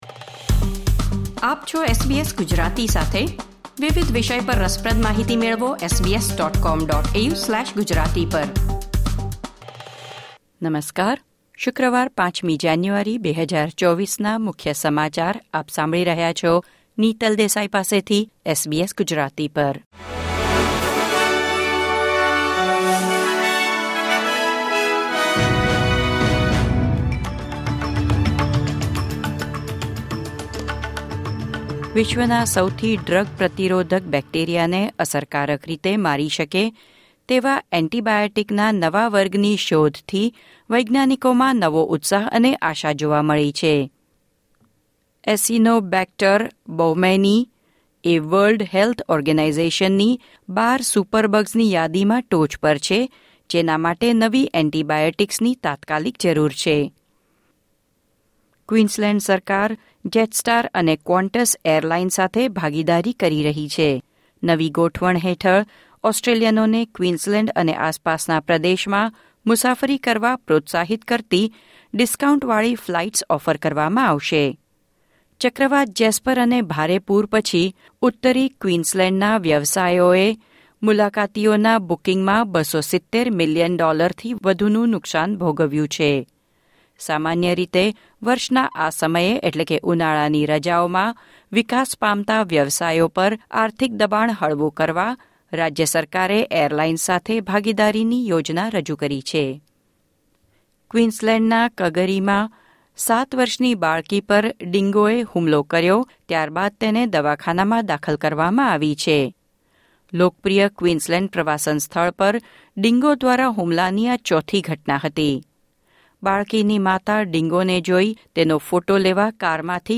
SBS Gujarati News Bulletin 5 January 2024